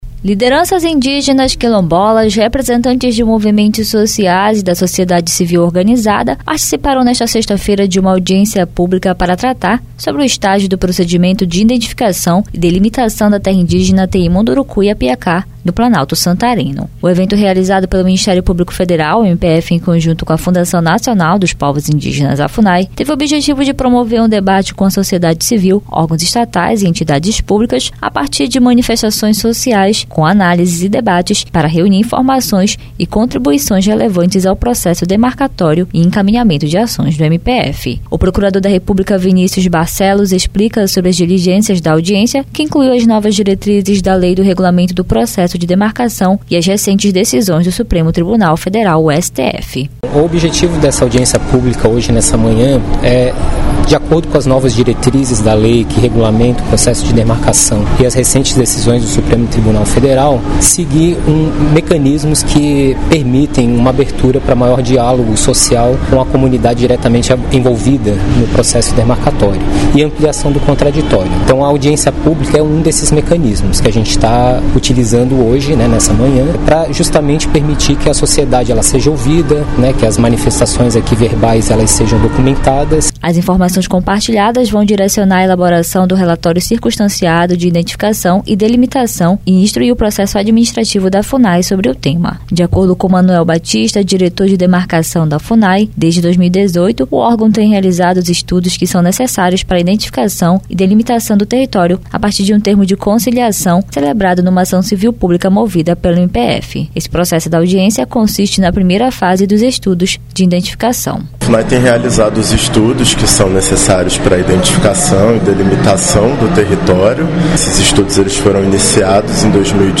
Audiência pública debate demarcação de Terra Indígena em Santarém